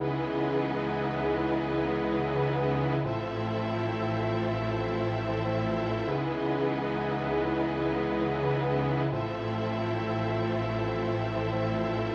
sad jazz string chords_79bpm.wav